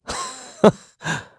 Clause_ice-Vox_Happy1_kr.wav